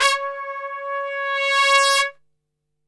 C#3 TRPSWL.wav